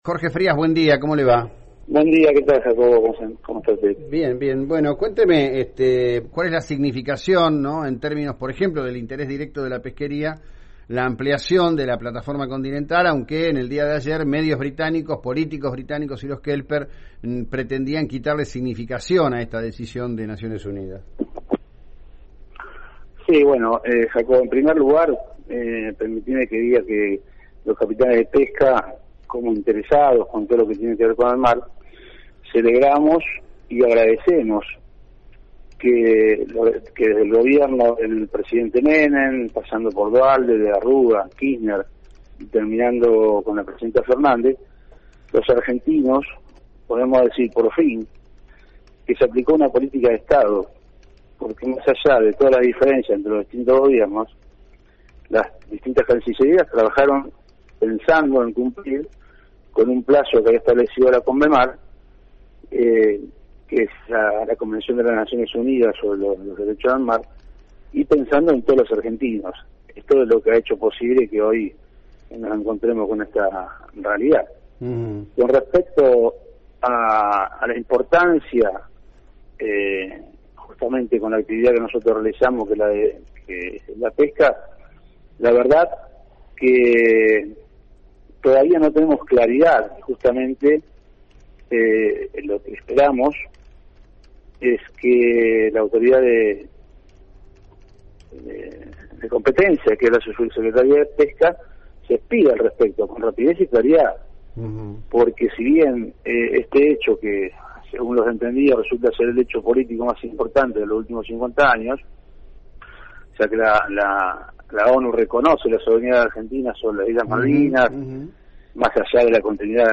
Audio de la entrevista radial